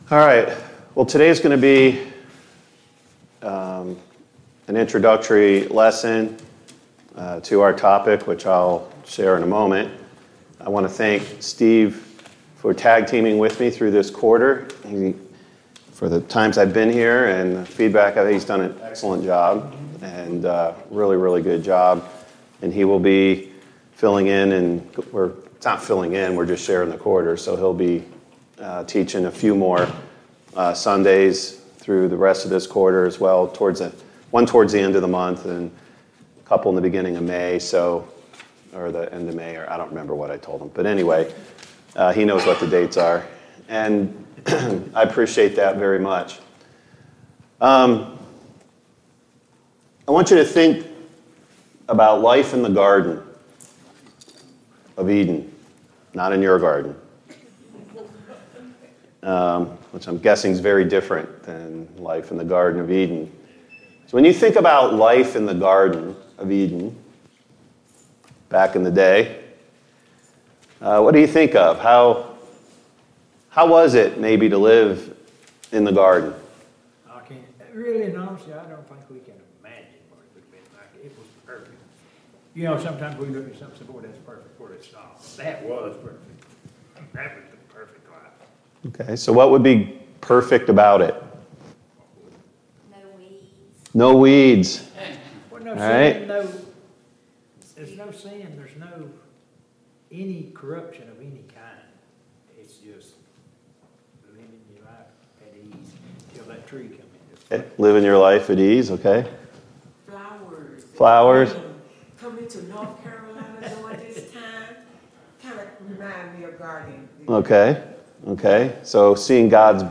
Living outside of the Garden Service Type: Sunday Morning Bible Class Topics: Sin & Temptation « 71.